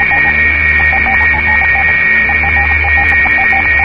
Corrupted broadcaster
broadcast3.ogg